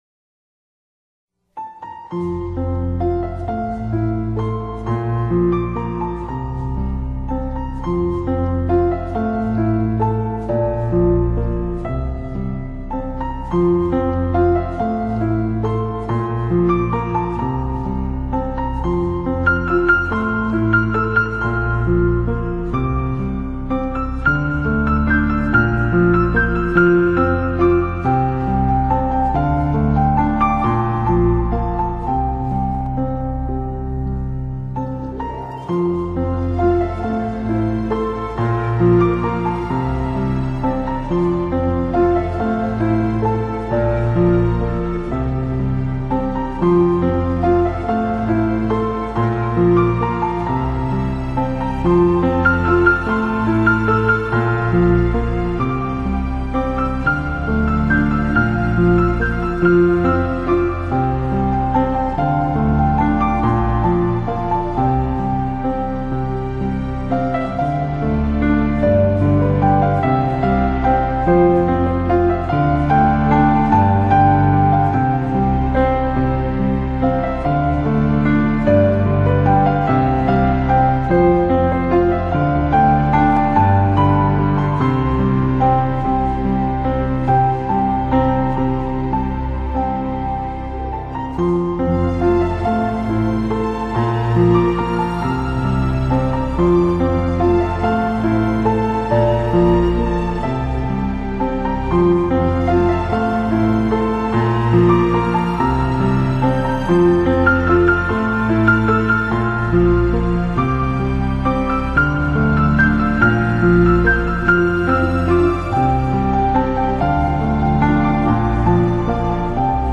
十分动听，清晰明快，让人心醉。